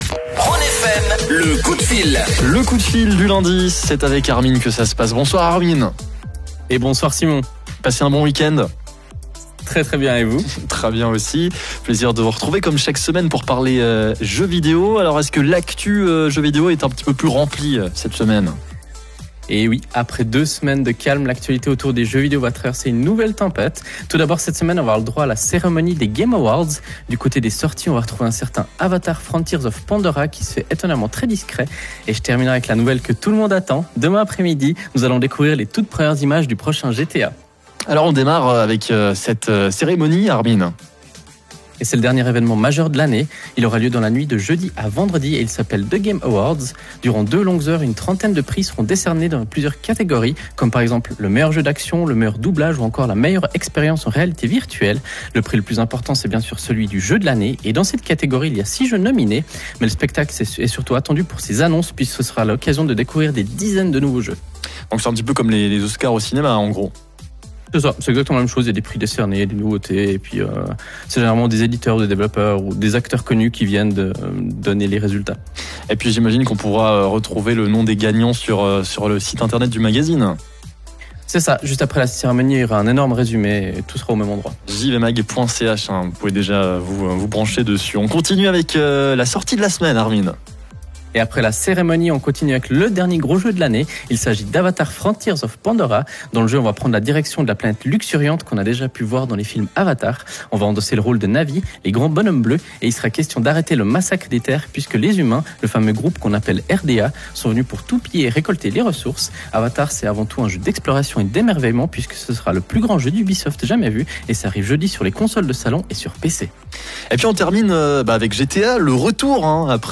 De quoi nous donner du grain à moudre pour notre chronique radio hebdomadaire.